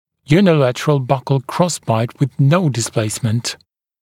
[juː n ɪ’lætərəl ˈbʌkl ‘krɔ s b a ɪt wɪð n əu dɪ s ’pleɪ s mə n t] [йу:ни’лэтэрэл ˈбакл ‘кросбайт уиз ноу дис’плэйсмэнт]